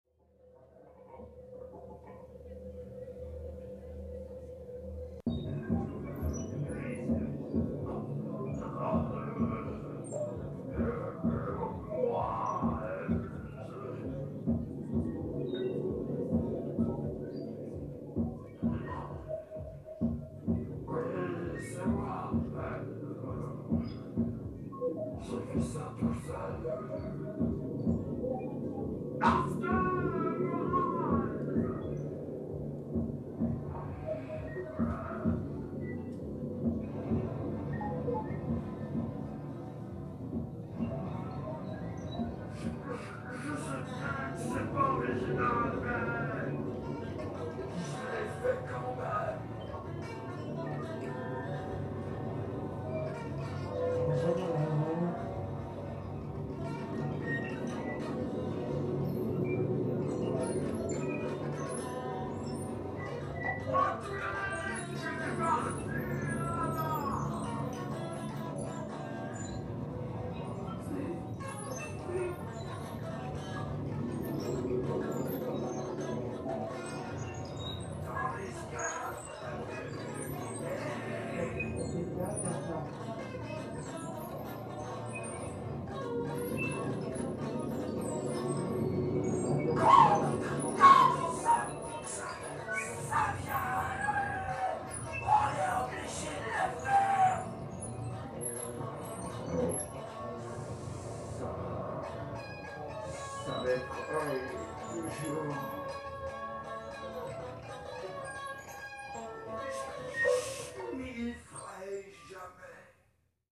hoquets et gutturations